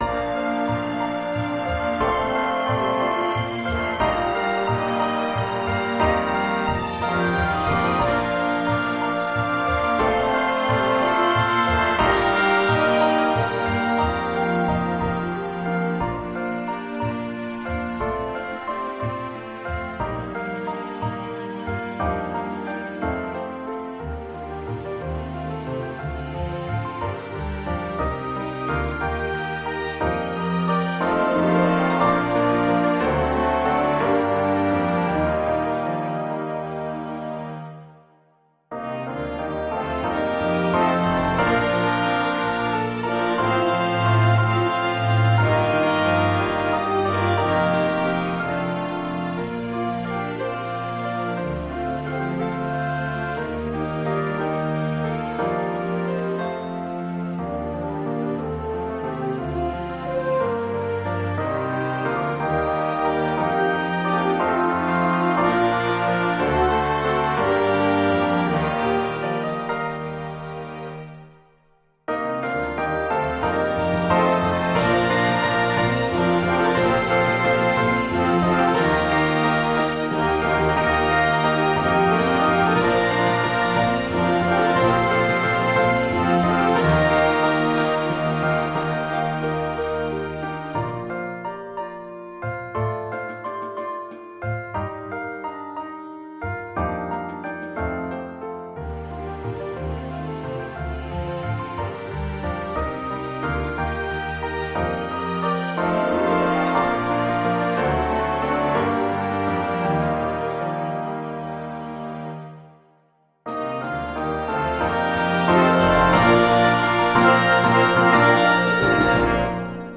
018 Поют сердца-колокола - Demo without Vox.mp3